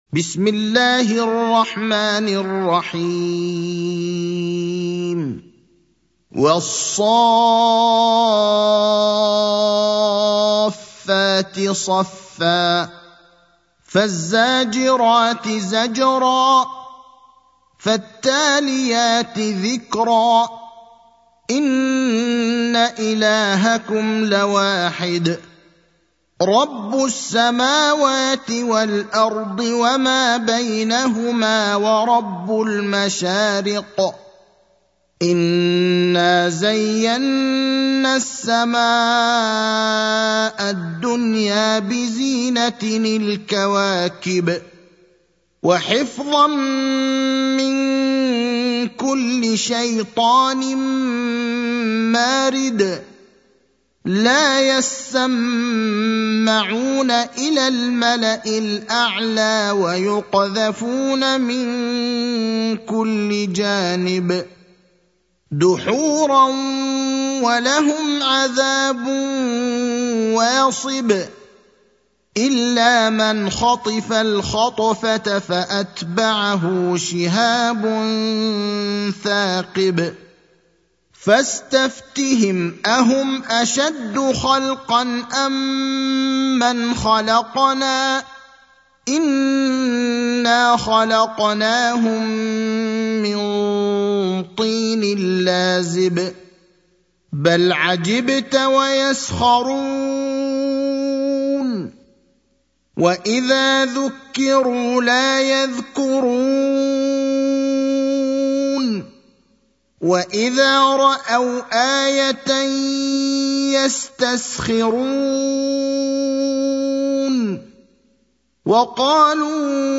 المكان: المسجد النبوي الشيخ: فضيلة الشيخ إبراهيم الأخضر فضيلة الشيخ إبراهيم الأخضر الصافات (37)